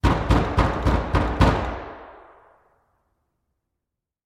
Звуки стуков в дверь
Кто-то стучится в дверь